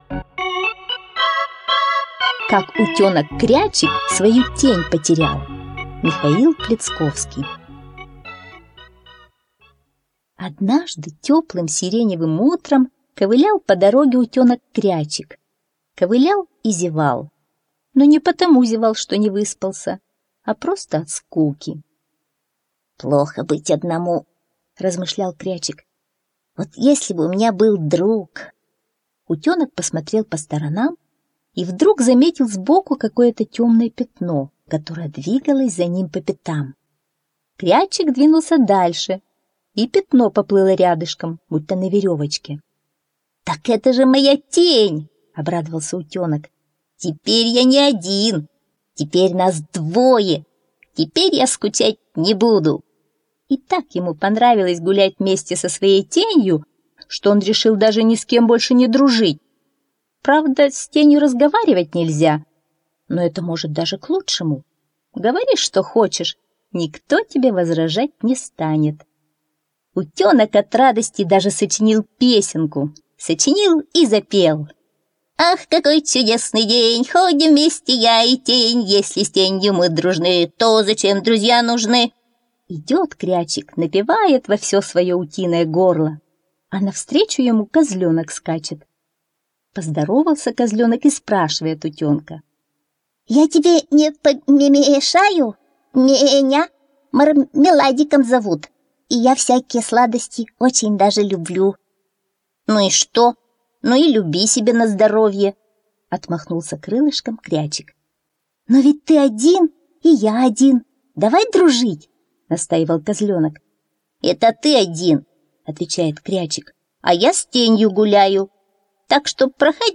Как утенок Крячик свою тень потерял - аудиосказка Пляцковского М. Как утенок Крячик нашел друзей, которые спасли его от неприятности!